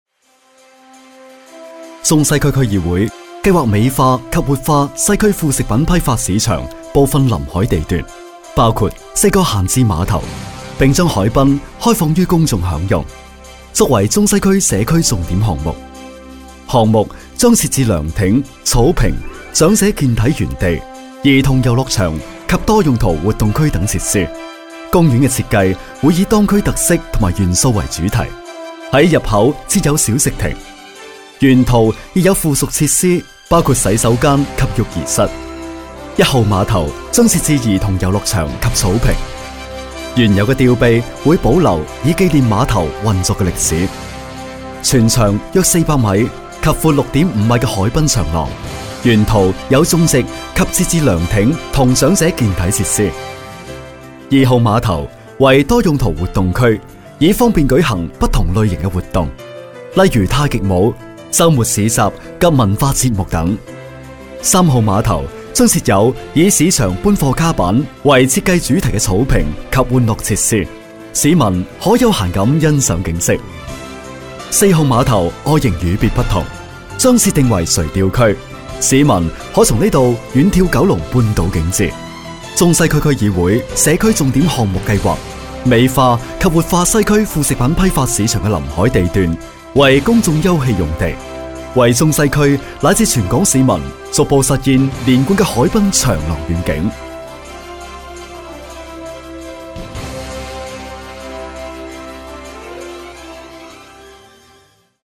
粤语地产配音欣赏
配音题材：粤语配音
配音员：男粤22